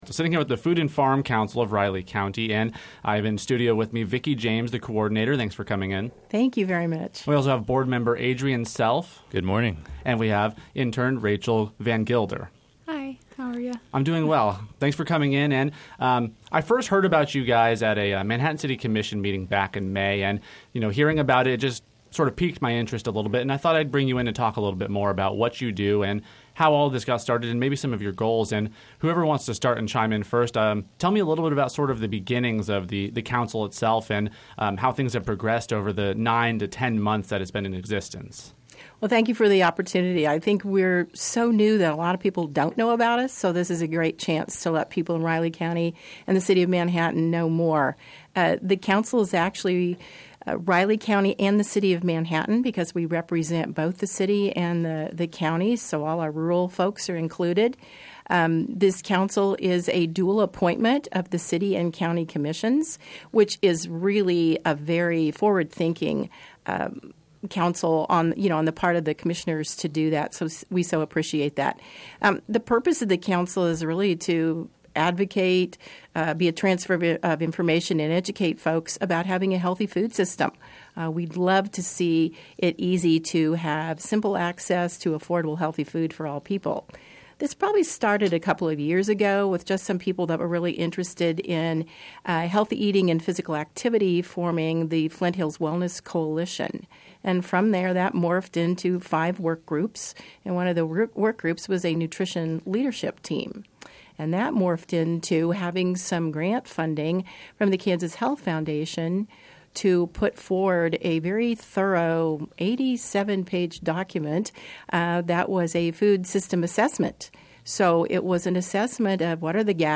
joined KMAN in-studio Thursday. Created more than 9 months ago, they discussed the progress they’ve made conducting interviews with stakeholder groups involved in the food system — from farmers to grocery businesses — as well as their goals and openings they have on the board.